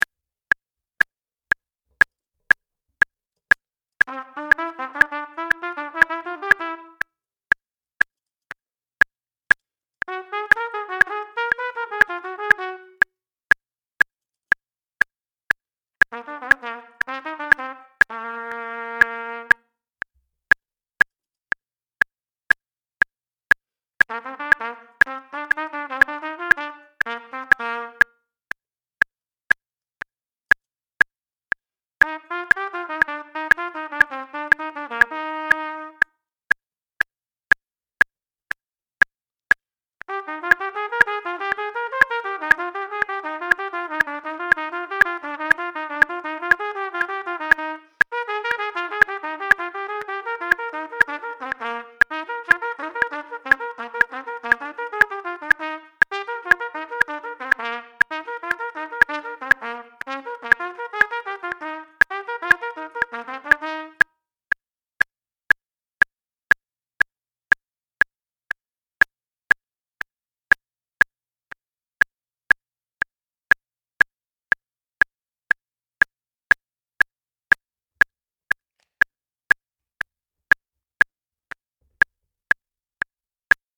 Triple Tonguing Exercises